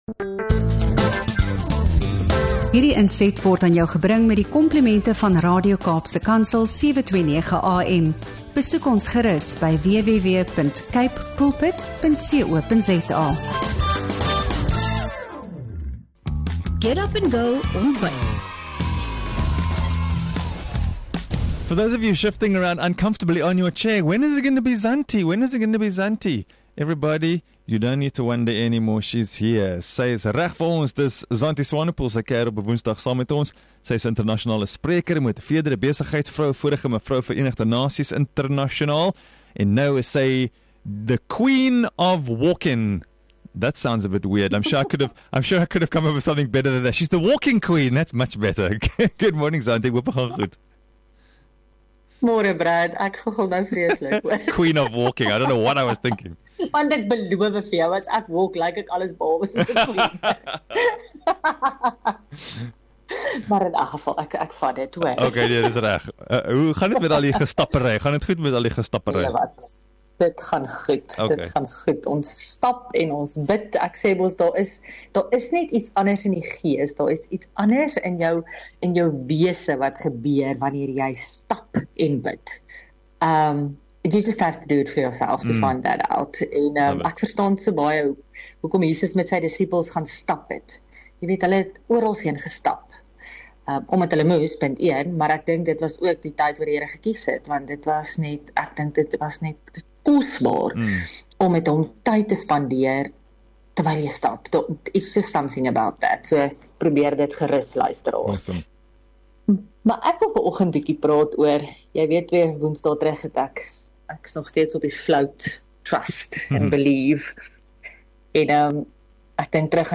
’n Eerlike, hoopvolle en lewensveranderende gesprek wat jou sal nooi om weer huis toe te kom — na die Vader se hart.